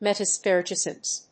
音節met・emp・sy・cho・sis 発音記号・読み方
/mətèm(p)sɪkóʊsɪs(米国英語), mètəm(p)sɑɪkˈəʊɪs(英国英語)/